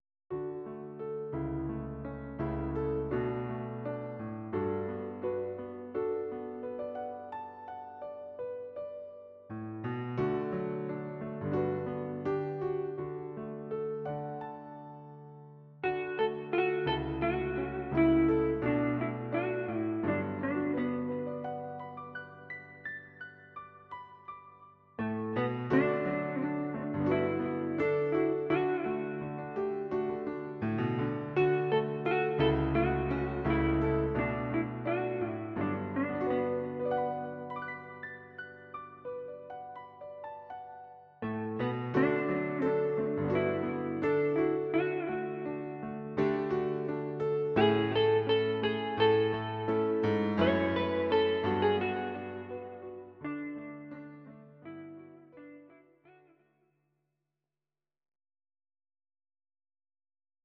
Audio Recordings based on Midi-files
Pop, Instrumental, 1970s